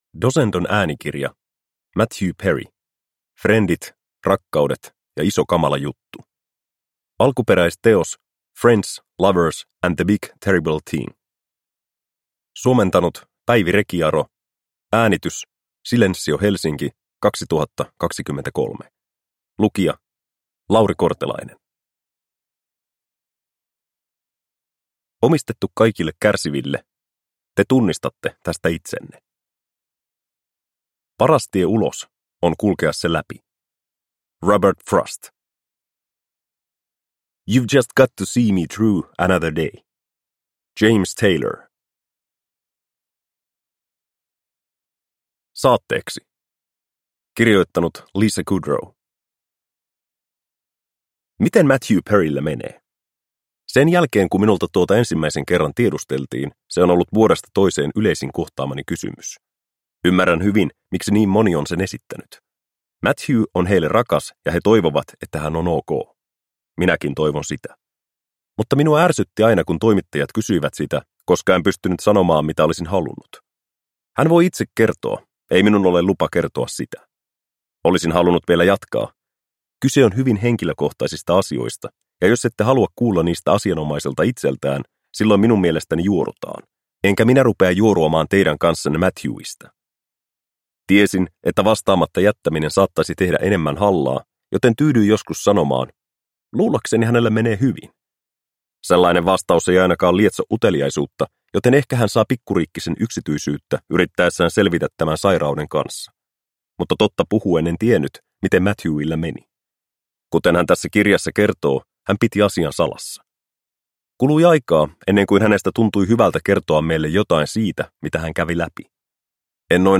Frendit, rakkaudet ja iso kamala juttu – Ljudbok – Laddas ner